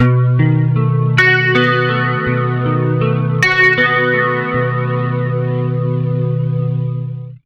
80MAJARP B-L.wav